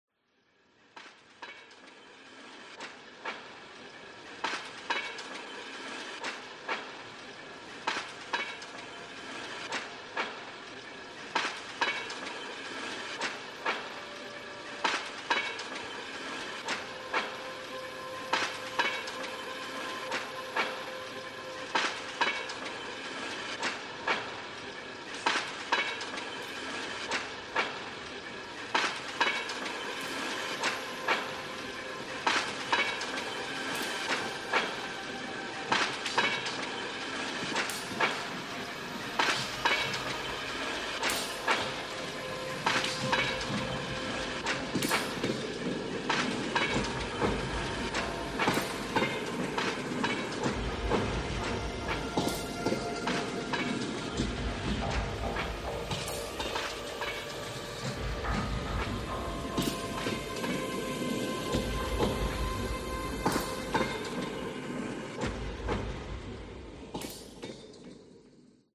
Sunetul și muzica drezinei care duce în Zonă
versiunea audio 5.1